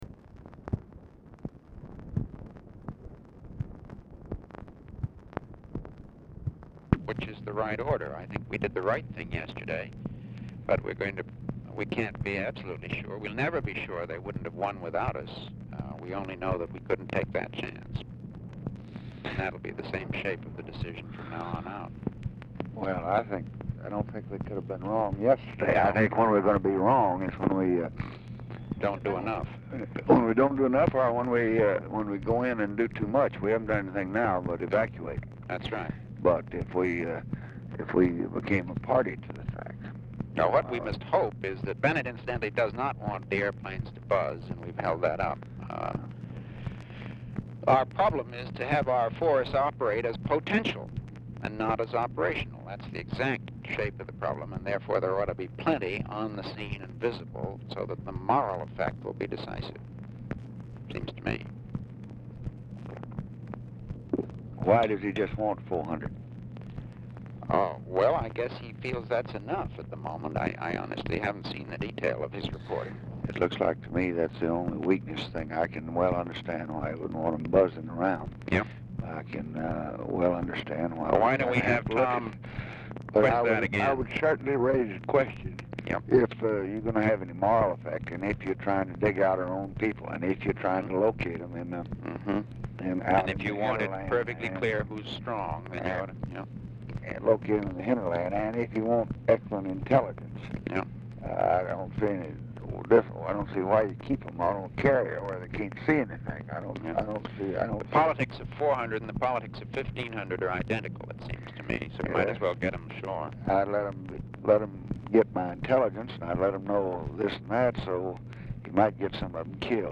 Mansion, White House, Washington, DC
Telephone conversation
Dictation belt